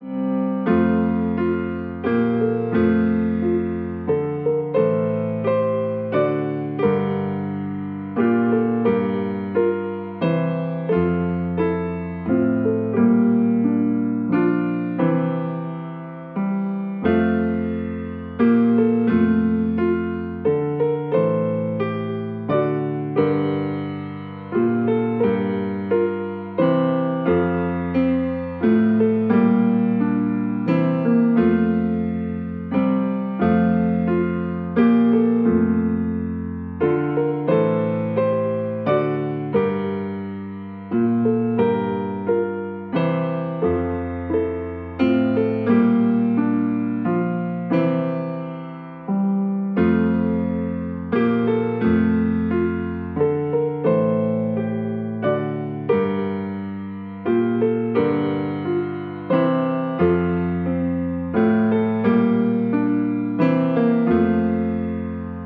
interprétés dans une atmosphère chaleureuse et raffinée.